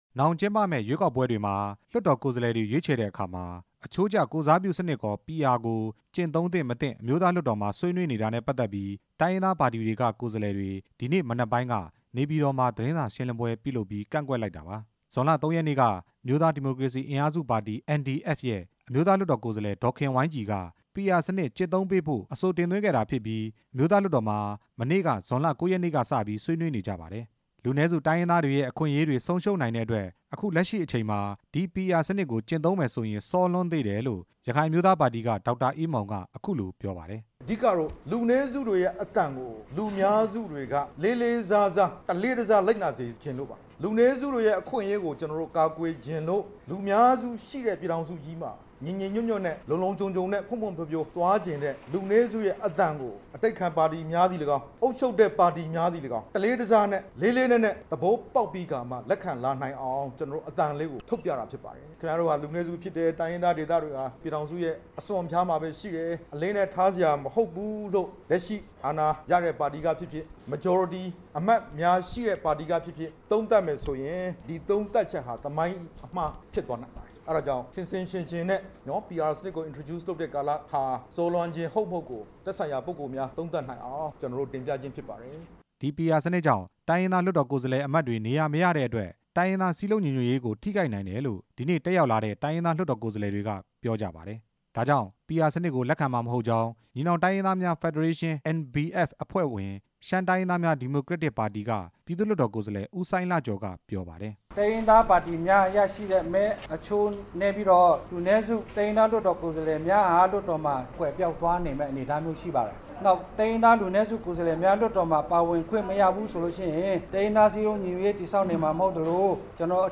နေပြည်တော်ရှိ  မောခမ်းနွံစားသောက်ဆိုင်မှာ ကျင်းပတဲ့ သတင်းစာရှင်းလင်းပွဲမှာ